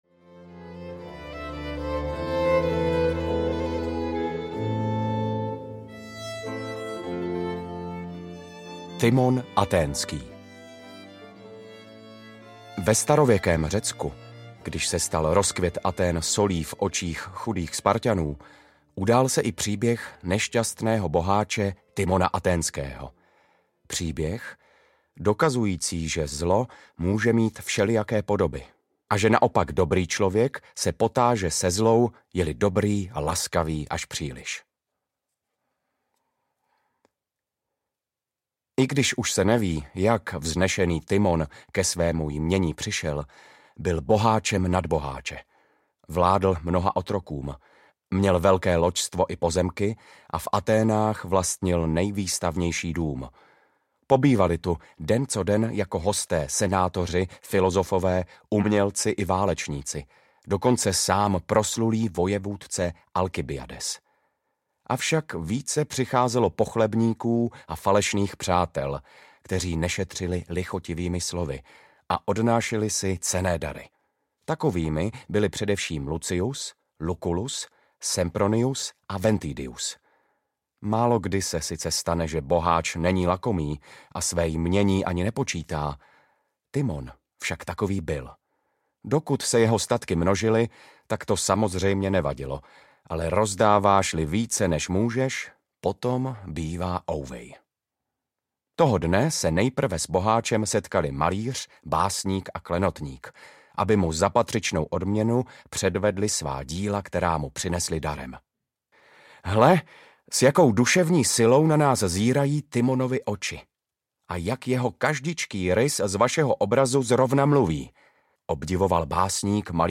Příběhy ze Shakespeara audiokniha
Ukázka z knihy